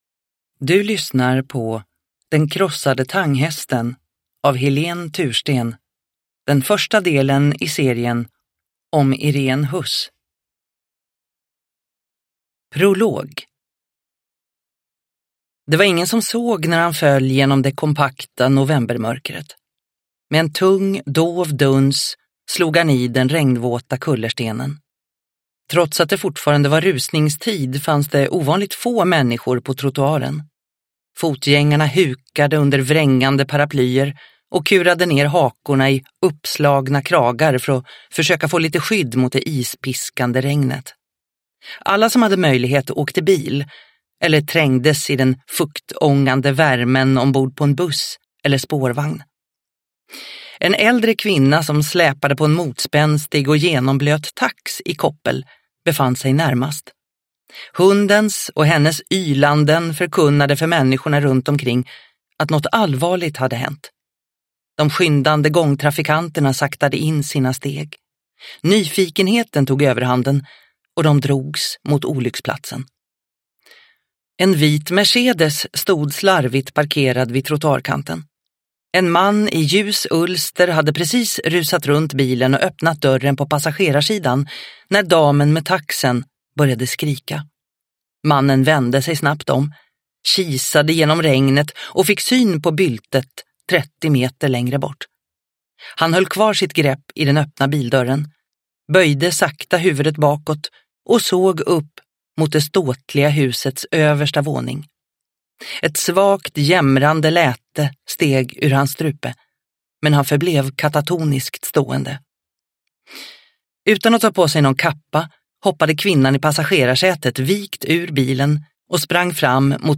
Den krossade tanghästen – Ljudbok – Laddas ner